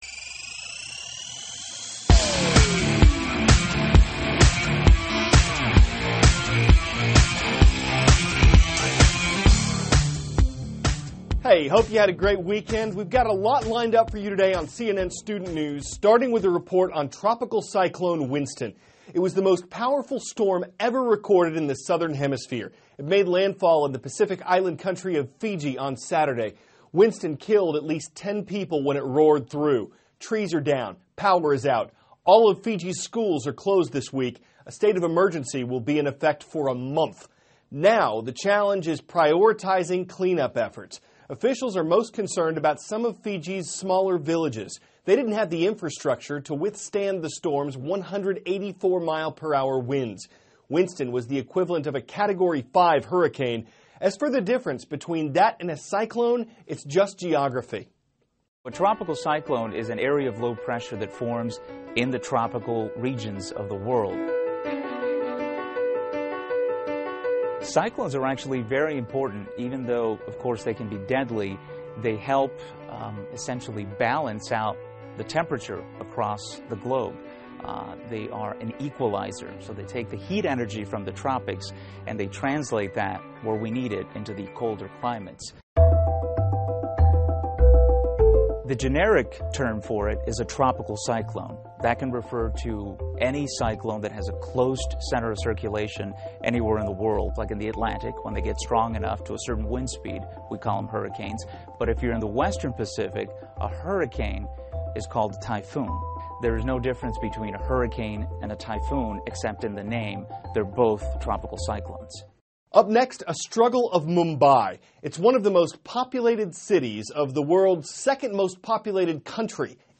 (CNN Student News) -- February 22, 2016 Monster Cyclone Strikes Fiji; A Mountain of Trash Towers Over Mumbai; Winners and Loser from South Carolina, Nevada Contests THIS IS A RUSH TRANSCRIPT.